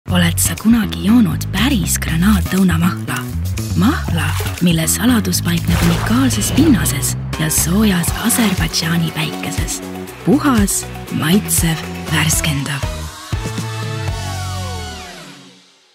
Conversational, young, mature, raspy, seductive, condescending, friendly, cool, warm, softspoken, calm, soothing, motherly, whispery, breathy, monotone, dramatic, funny, mysterious, emotional, youthful, low, genuine, authentic, neutral, intense.